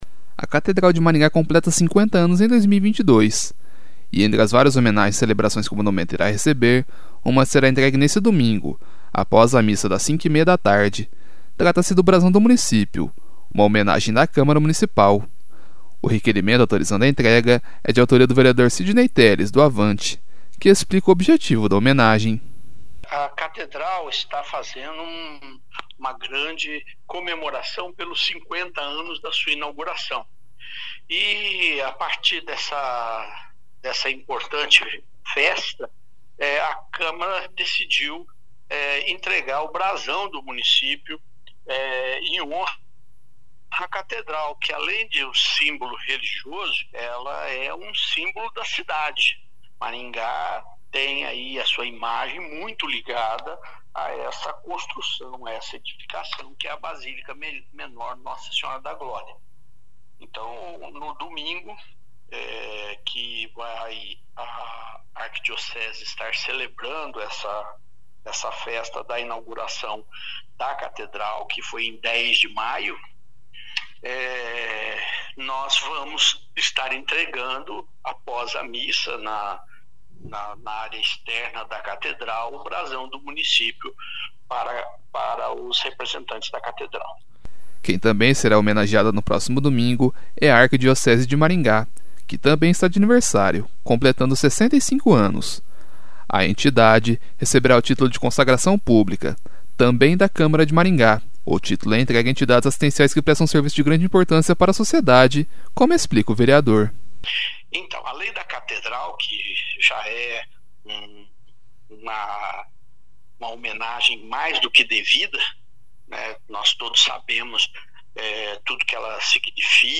O requerimento autorizando a entrega é de autoria do vereador Sidnei Telles (Avante), que explica o objetivo da homenagem.